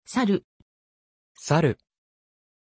Pronunția numelui Saúl
pronunciation-saúl-ja.mp3